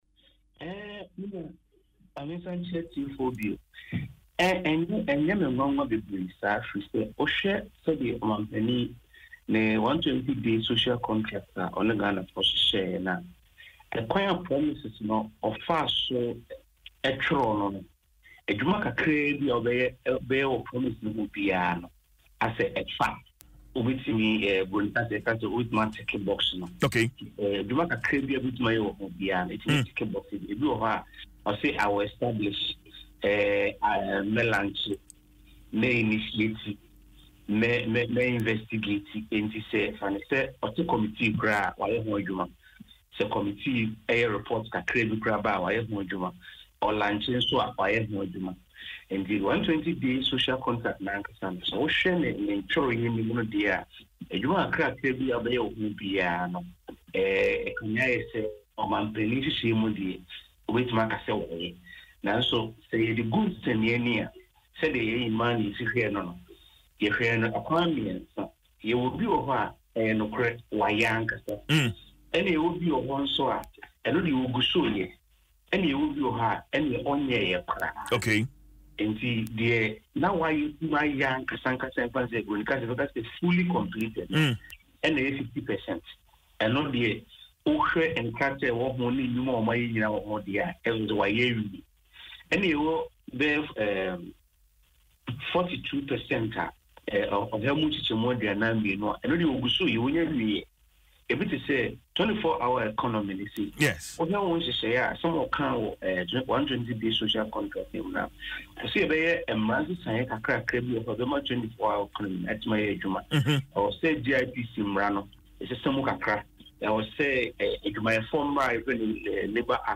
interview on Adom FM’s Dwaso Nsem